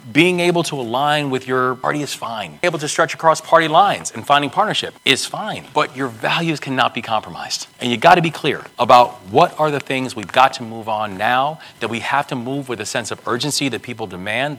At a recent talk held at the National Press Club in Washington, Governors Wes Moore and Spencer Cox of Utah discussed how they approach governing, coming from different parties.  Governor Moore said that bipartisanship and common ground is what has helped him to accomplish goals he has set in Maryland…